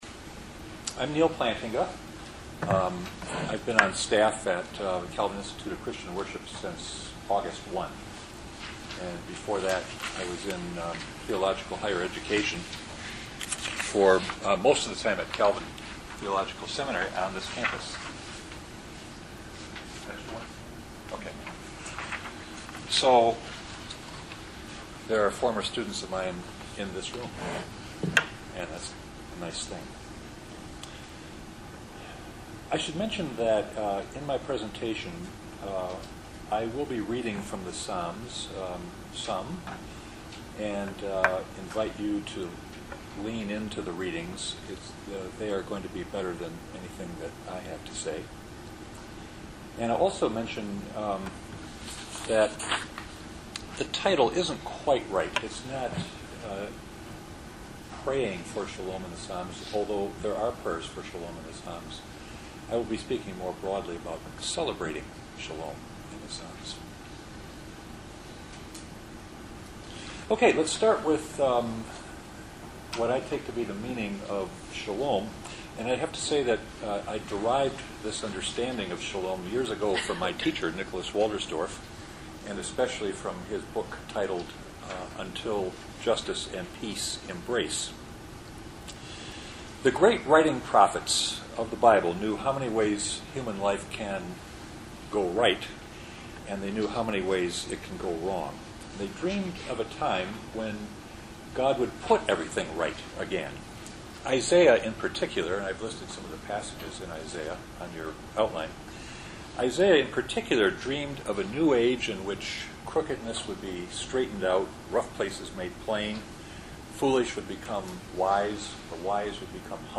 Day of Learning June 19, 2013 | Calvin Institute of Christian Worship
Check out these mp3 recordings of worship renewal workshops: